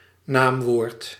Ääntäminen
UK : IPA : /naʊn/ US : IPA : /naʊn/